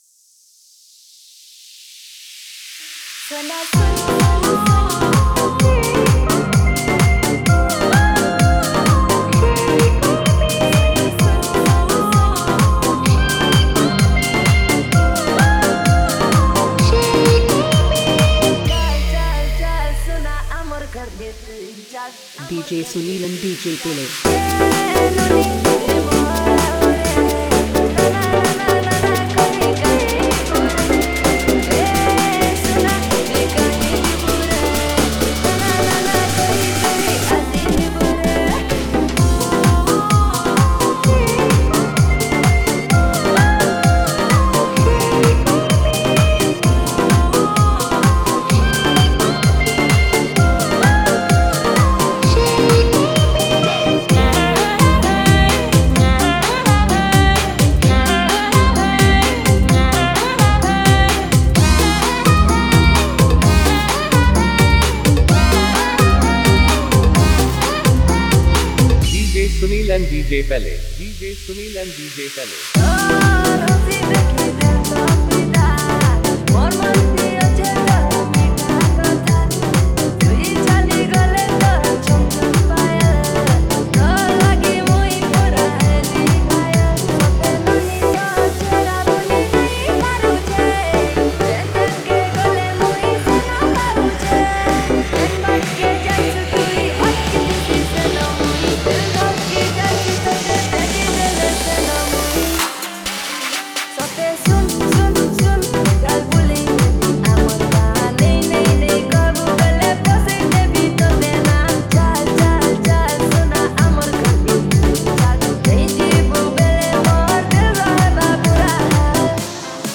Category:  Sambalpuri Dj Song 2022